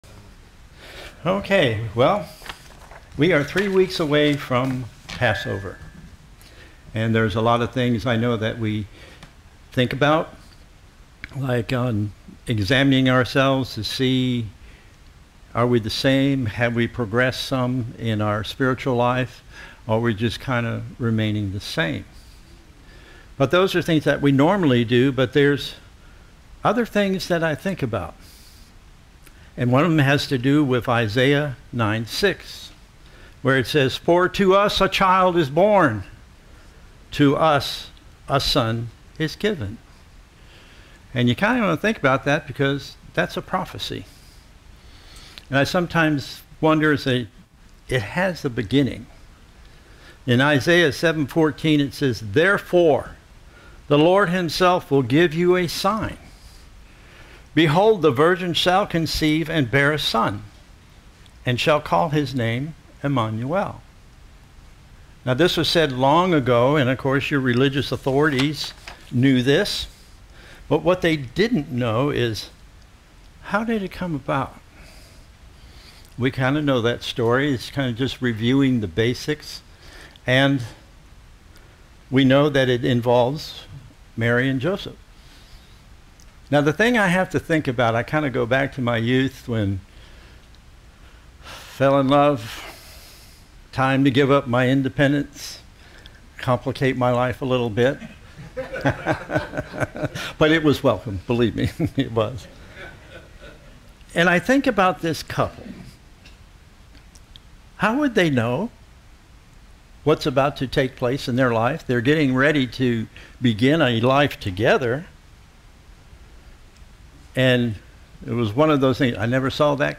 Given in Ft. Lauderdale, FL